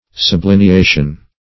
Search Result for " sublineation" : The Collaborative International Dictionary of English v.0.48: Sublineation \Sub*lin`e*a"tion\, n. A mark of a line or lines under a word in a sentence, or under another line; underlining.